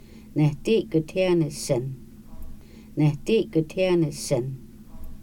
Individual audio recordings of Kaska words and phrases expressing encouragement. This subset of the original Encouragement Deck focuses on positive traits and kindness to the self.